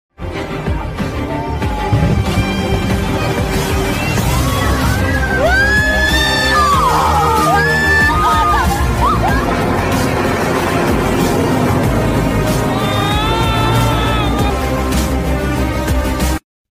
Coldrink Bottle Checking with Car sound effects free download